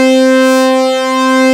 SYN MMS1.wav